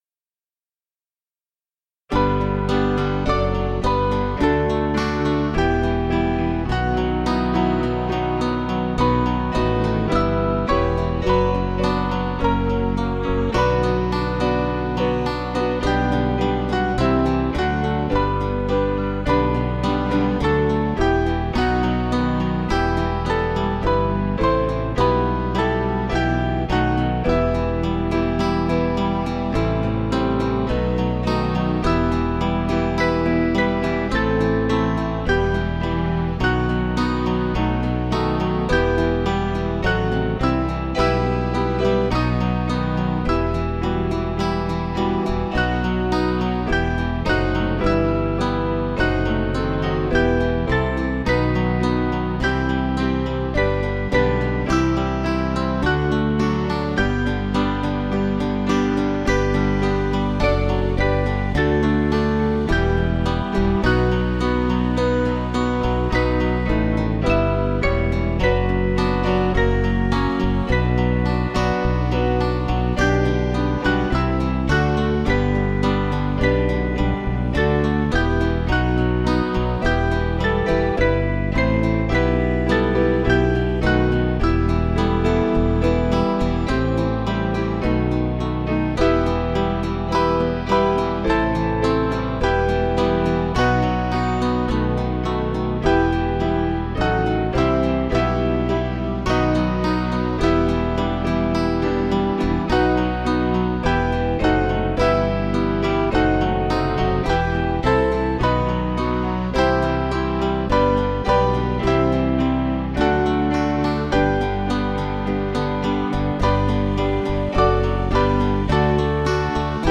Small Band
(CM)   4/Em